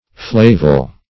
Search Result for " flavol" : The Collaborative International Dictionary of English v.0.48: Flavol \Fla"vol\, n. [L. flavus yellow + -oil.]